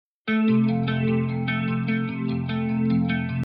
• Качество: 320, Stereo
гитара
спокойные
без слов
электрогитара